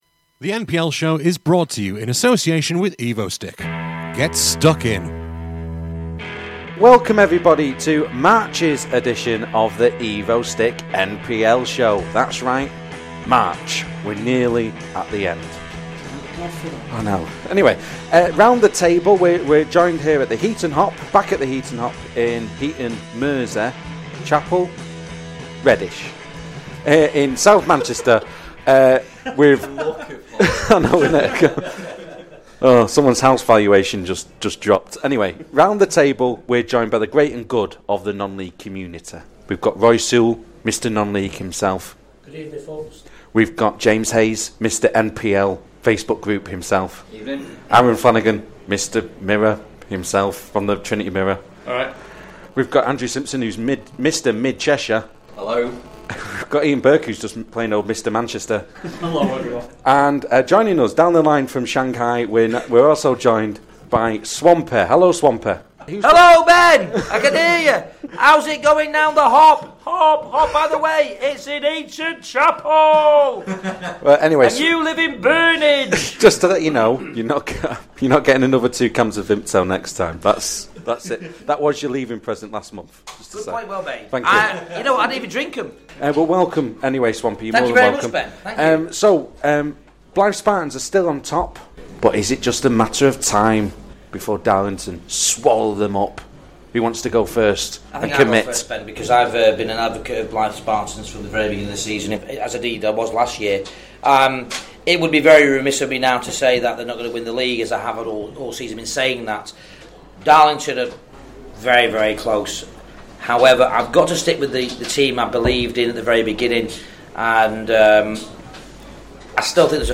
The Evo-Stik NPL Show is back for an outdated debate about all things promotion, playoffs, and relegation.
This programme was recorded at the Heaton Hops in Manchester on Wednesday 30th March 2016.